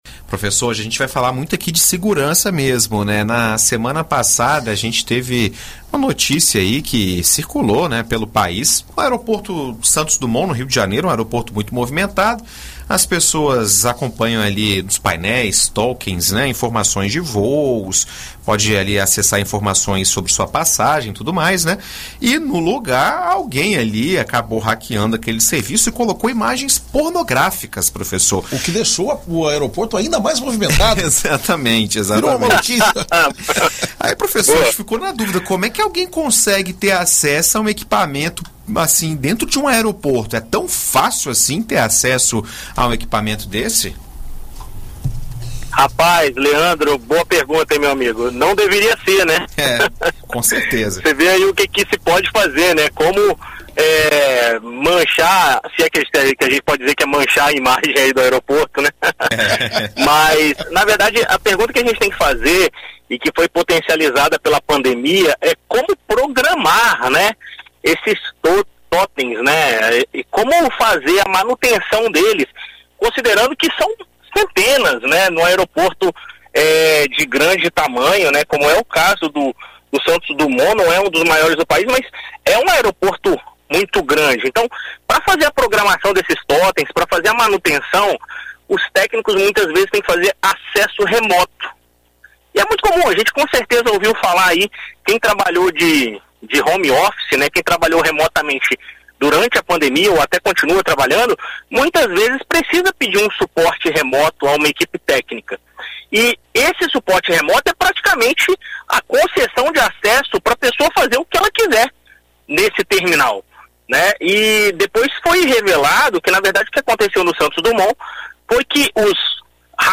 Em entrevista à BandNews FM Espírito Santo nesta terça-feira